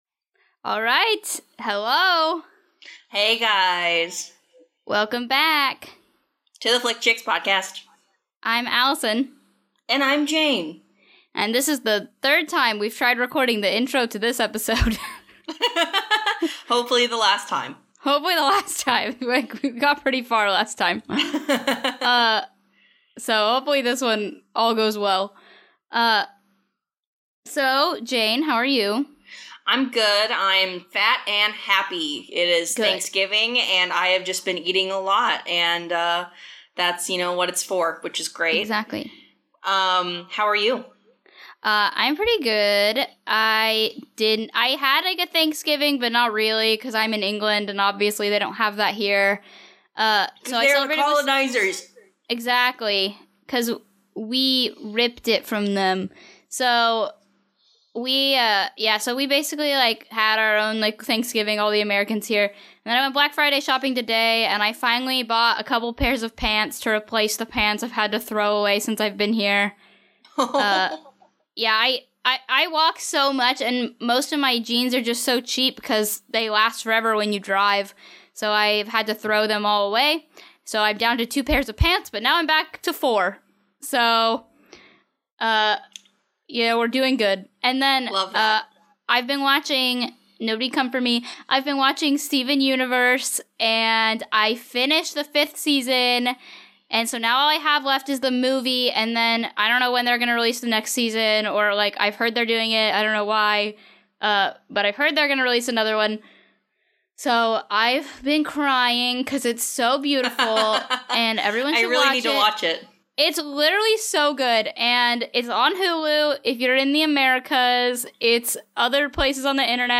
ATTENTION: The audio problems we have been having are fixed! Our audio is no longer distorted.